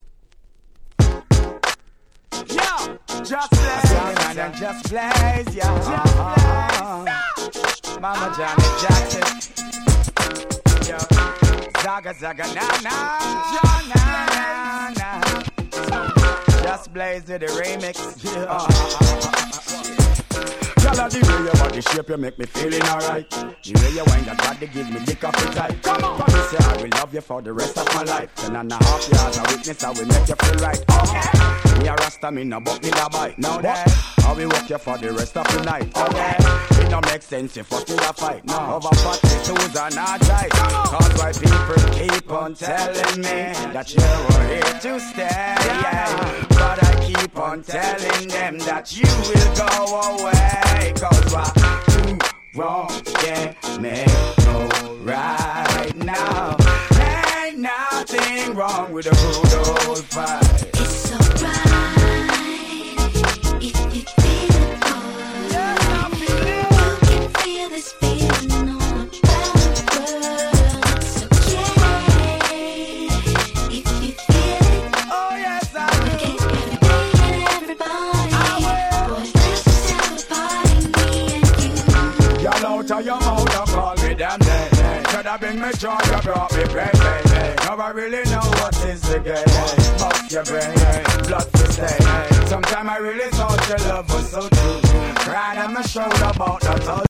02' Smash Hit R&B / Reggae !!
オリジナルよりもまったりとした感じ、凄く夏っぽくて◎！！